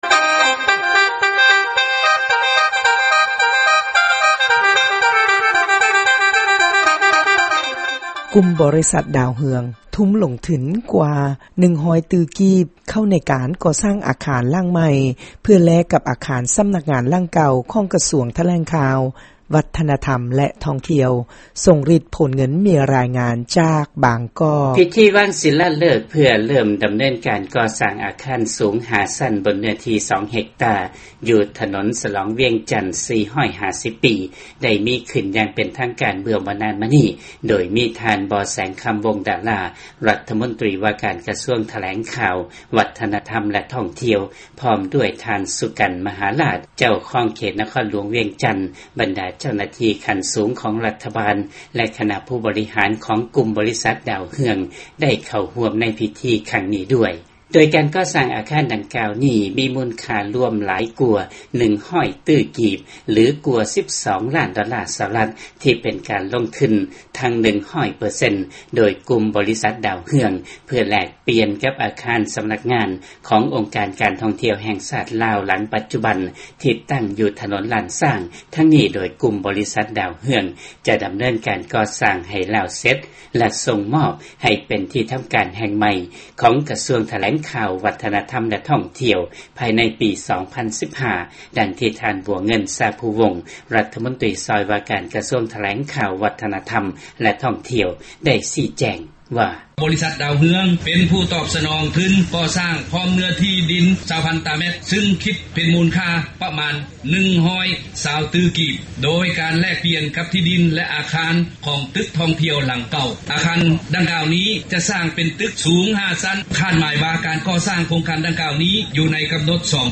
ຟັງລາຍງານບໍລິສັດຜະລິດກາເຟໃນລາວ